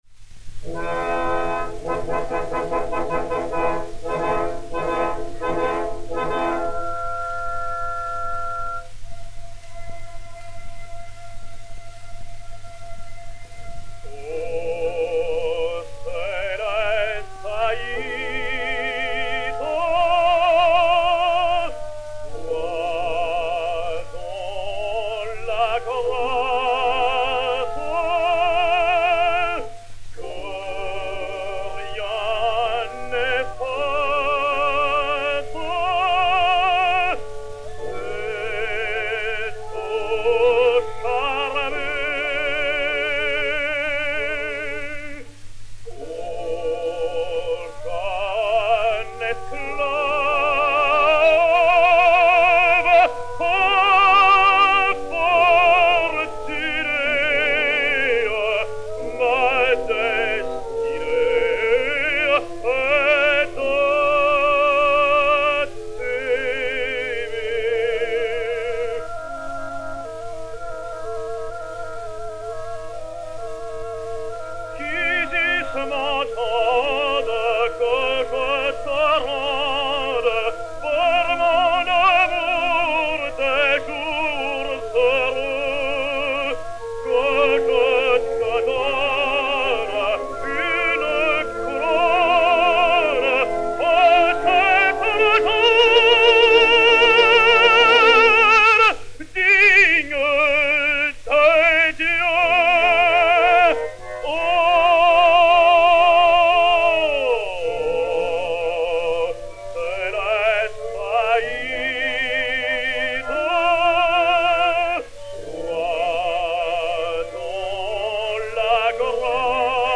M. Franz, de l'Opéra, né à Paris.
Paul Franz (Roméo) et Orchestre